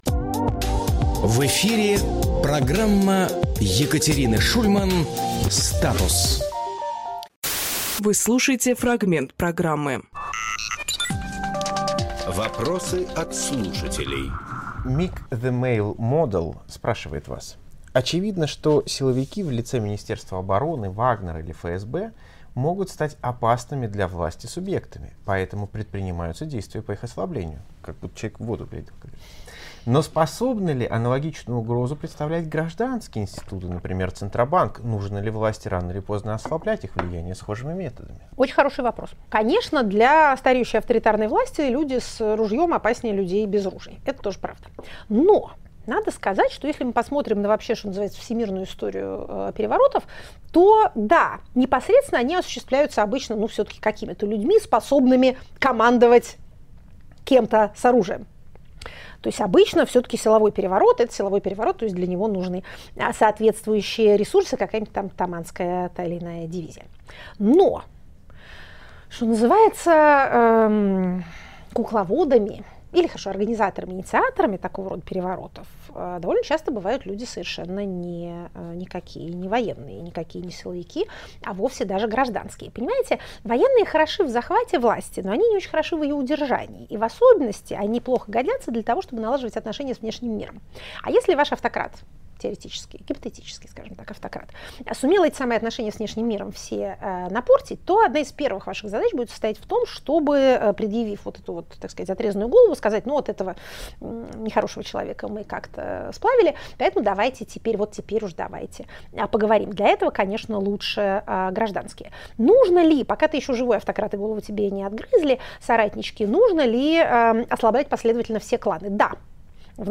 Екатерина Шульманполитолог
Фрагмент эфира от 21.05.24